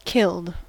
Ääntäminen
Ääntäminen US RP : IPA : /kɪld/ Haettu sana löytyi näillä lähdekielillä: englanti Käännöksiä ei löytynyt valitulle kohdekielelle.